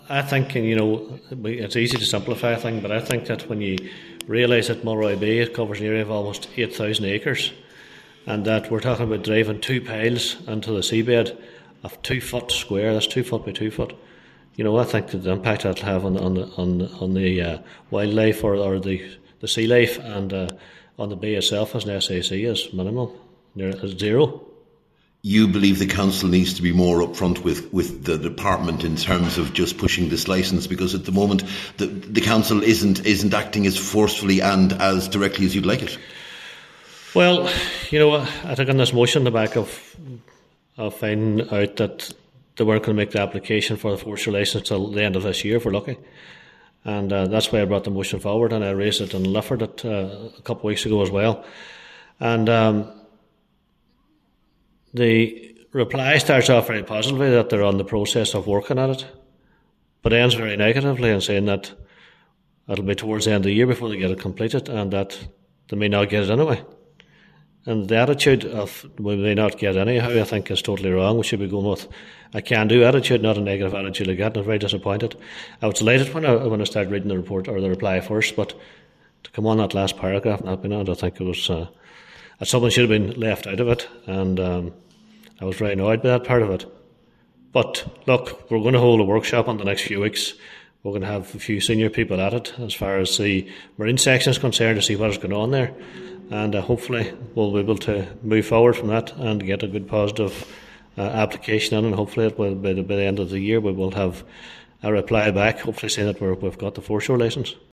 Cllr Blaney says, given the potential this project has to improve the area, the delay makes no sense: